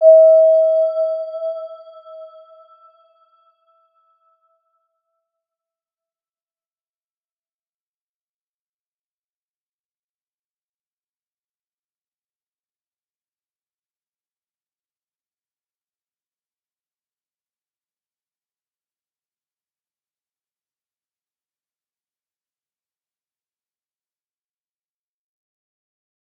Round-Bell-E5-mf.wav